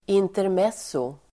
Ladda ner uttalet
Folkets service: intermezzo intermezzo substantiv, intermezzo , incident Uttal: [interm'es:o el. -m'et:so] Böjningar: intermezzot, intermezzon, intermezzona Synonymer: händelse Definition: oväntad händelse
intermezzo.mp3